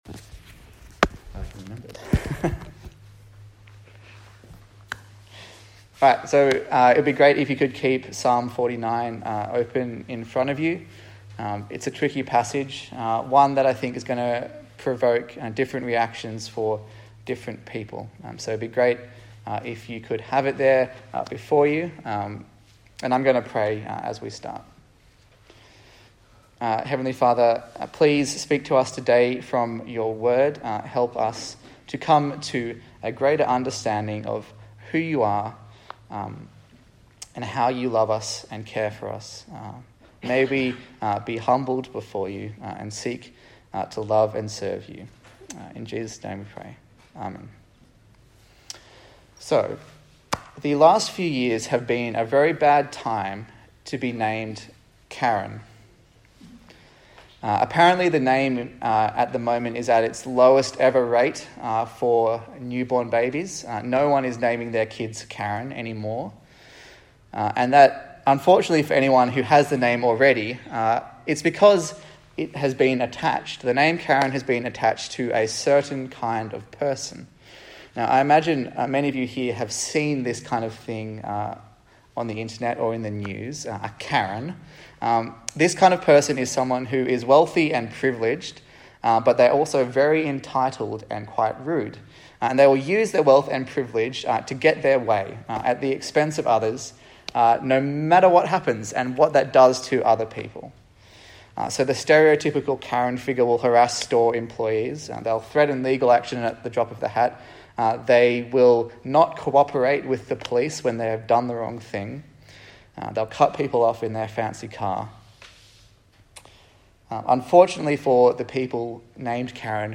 A sermon
Psalms Passage: Psalm 49 Service Type: Sunday Morning